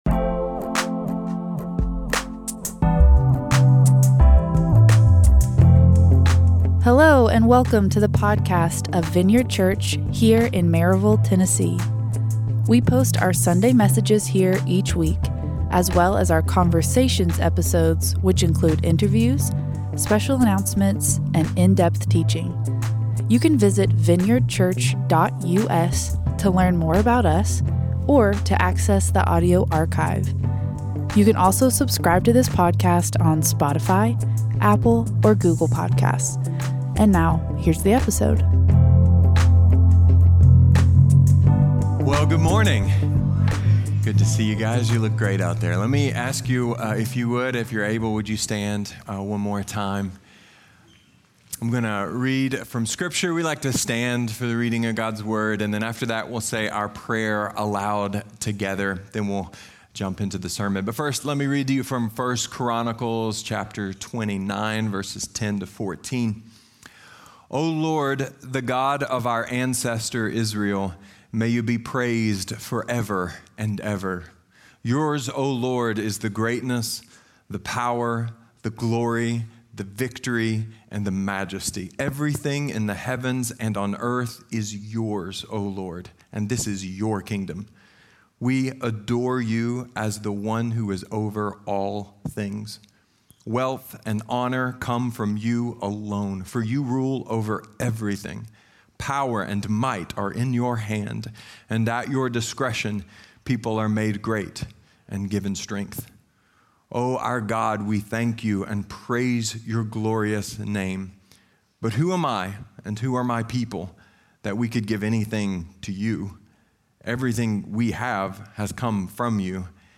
A sermon explaining why how much you have to give says NOTHING about how much you can contribute to the Kingdom of God .